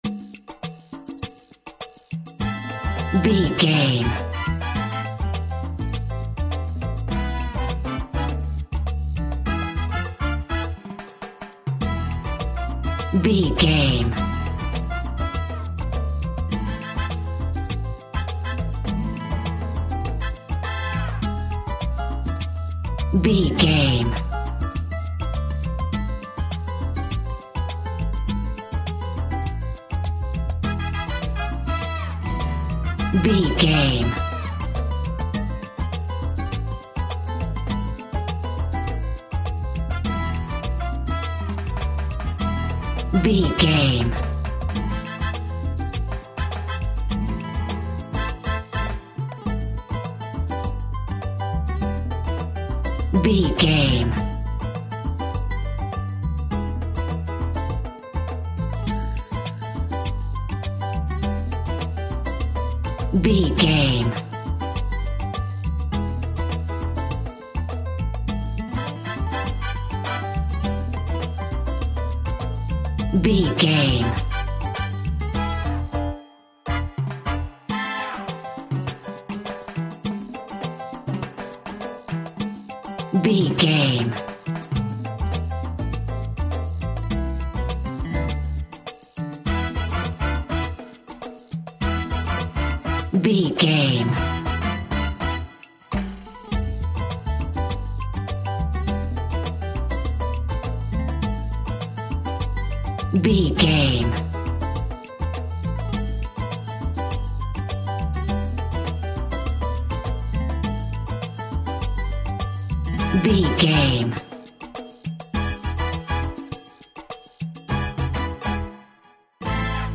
Uplifting
Aeolian/Minor
Funk
jazz
electric guitar
bass guitar
drums
hammond organ
fender rhodes
percussion